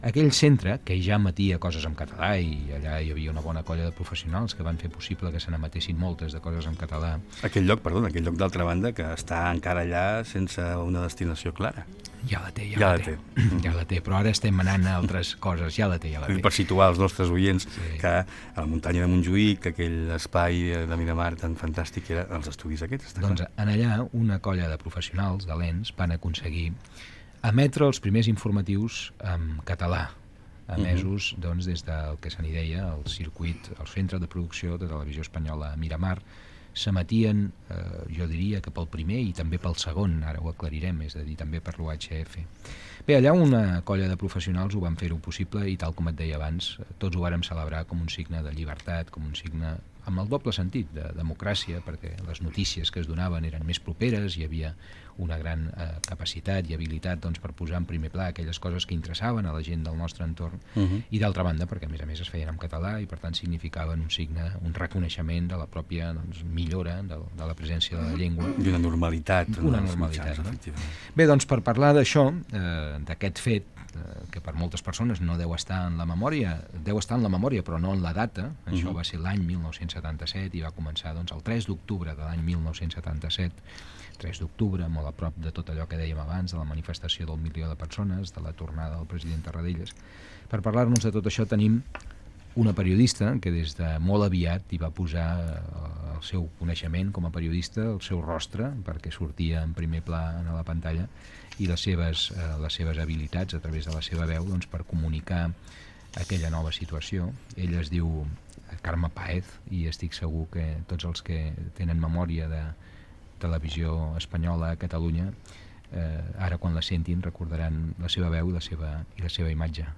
Fragment extret de l'arxiu sonor de COM Ràdio.